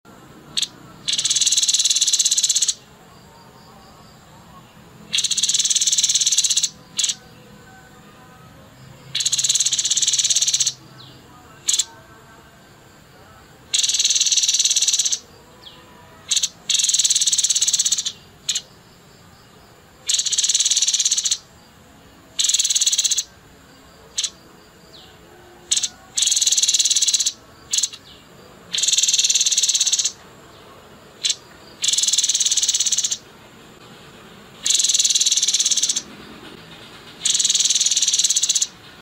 MASTERAN VIRAL PALING DICARI TEPUS